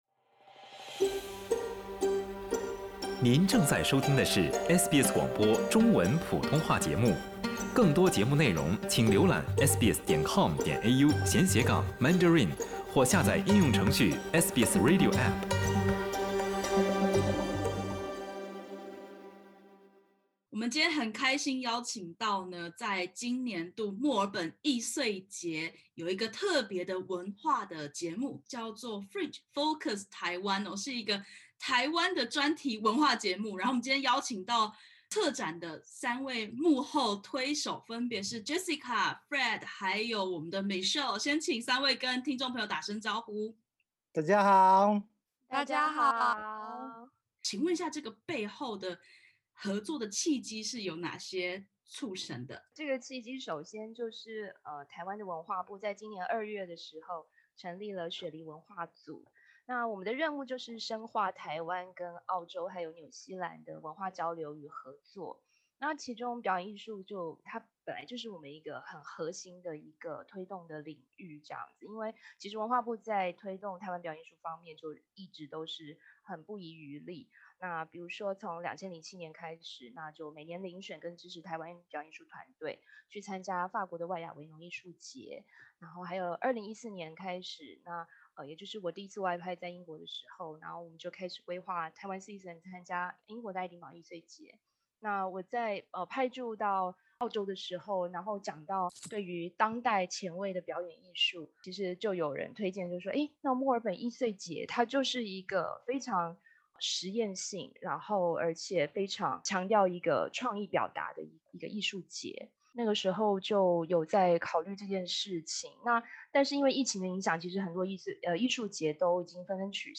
三组来自台湾的艺术团体，即将突破地理限制，在墨尔本艺穗节（Melbourne Fringe）“云”登台演出。点击首图收听完整采访音频。